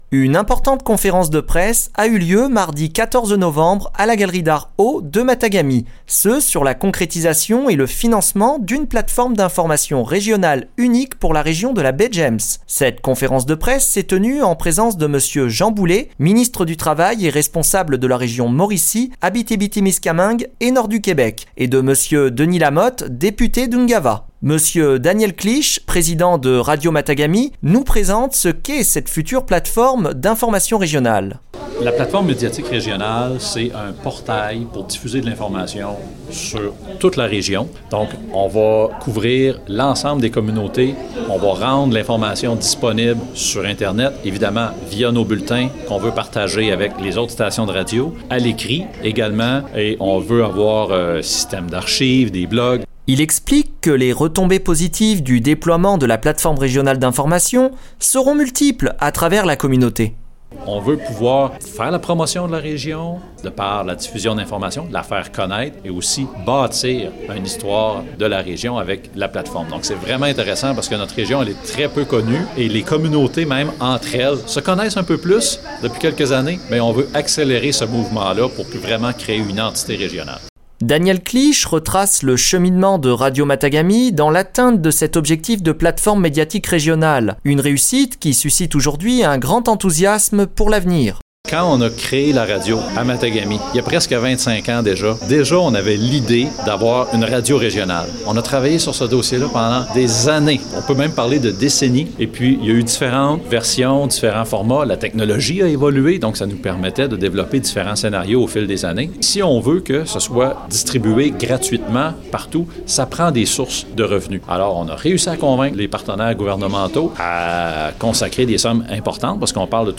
La tenue d’une conférence de presse à la galerie d’art « AU » de Matagami le mardi 14 novembre 2023 confirme la concrétisation et le financement d’une plateforme d’information régionale.